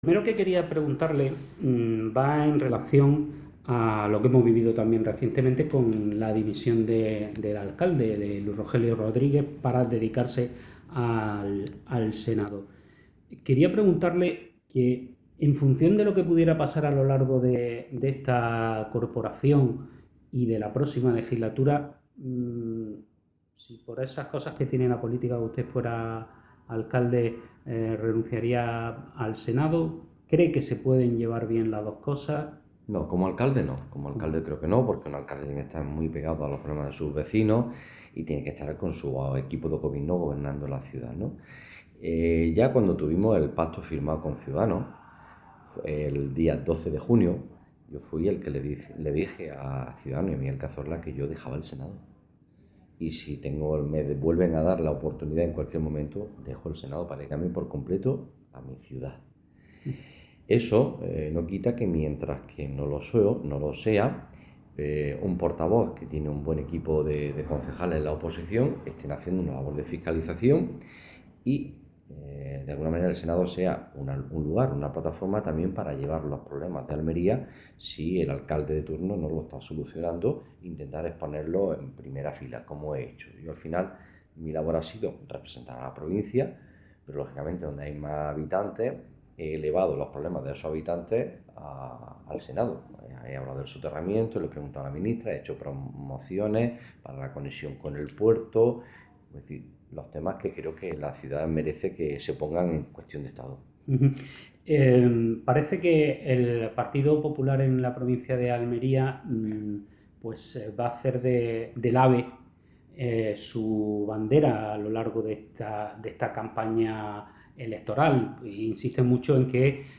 entrevistajcpn1.mp3